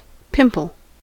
pimple: Wikimedia Commons US English Pronunciations
En-us-pimple.WAV